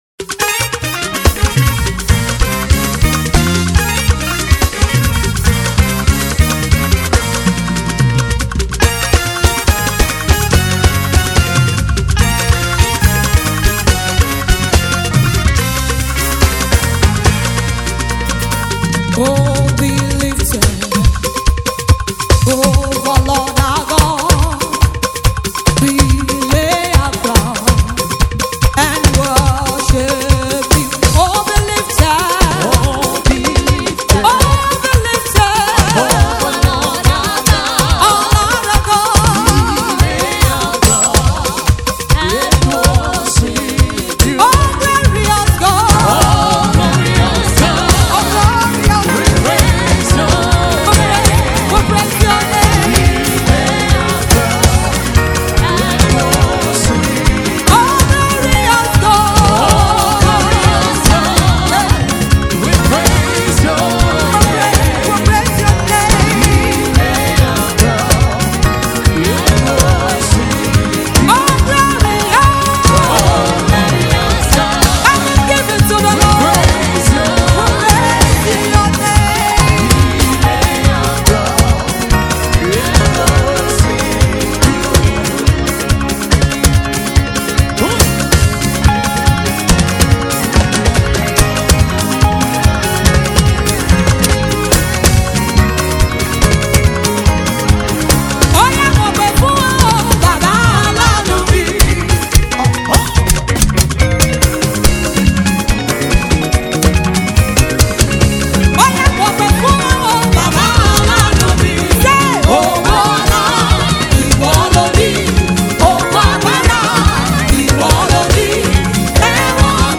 a gifted gospel singer and songwriter.